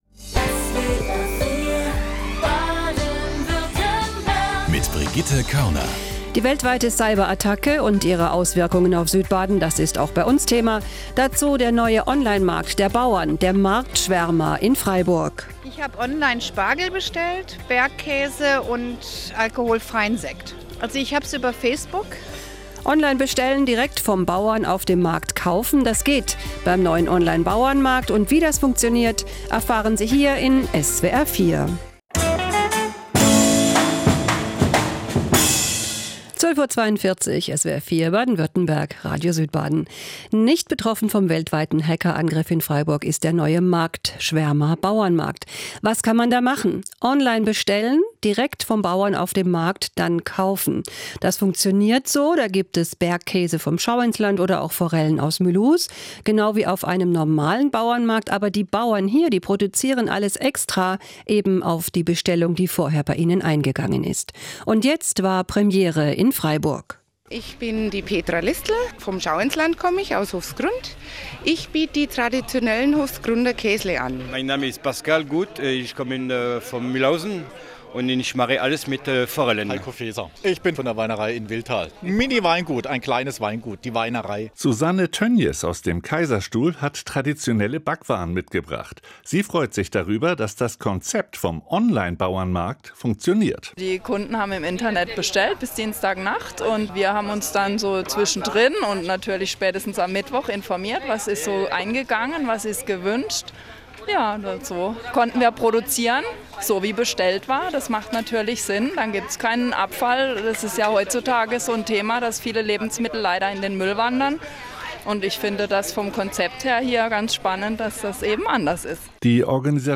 Radio-Echo: Die erste Freiburger Marktschwärmerei war auf SWR4 Radio Baden-Württemberg um halb eins in Radio Südbaden am Samstag 13. Mai 2017, 12:42 Uhr zu hören.
Viele der Erzeuger, aber auch die Kundenstimmen waren während der Eröffnung letzte Woche gefragt.